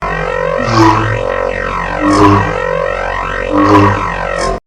Lightsaber Swings High Def
SFX
yt_ofK8Rl_MdsI_lightsaber_swings_high_def.mp3